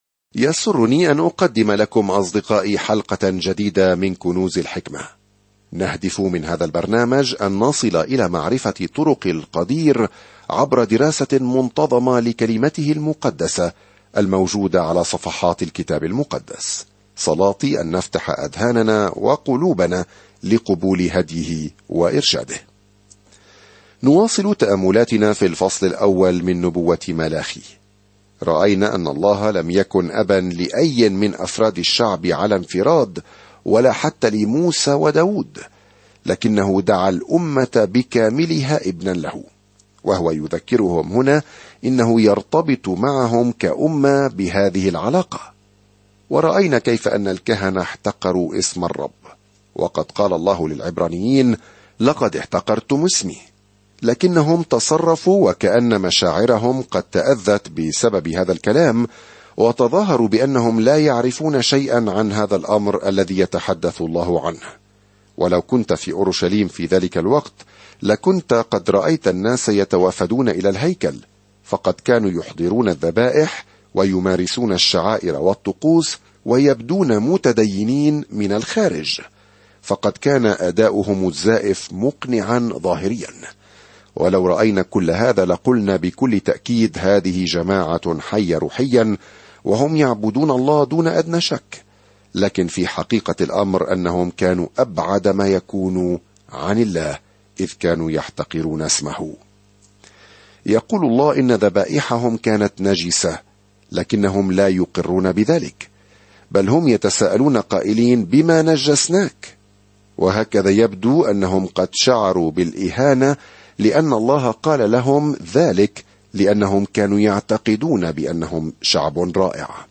الكلمة مَلَاخِي 9:1-14 يوم 2 ابدأ هذه الخطة يوم 4 عن هذه الخطة يذكّر ملاخي إسرائيل المنفصلة بأن لديه رسالة من الله قبل أن يتحملوا صمتًا طويلًا - والذي سينتهي عندما يدخل يسوع المسيح إلى المسرح. سافر يوميًا عبر ملاخي وأنت تستمع إلى الدراسة الصوتية وتقرأ آيات مختارة من كلمة الله.